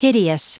Prononciation du mot hideous en anglais (fichier audio)
hideous.wav